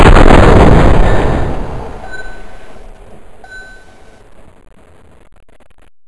mac10-fire.wav